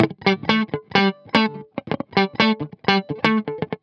10 GuitarFunky Loop A.wav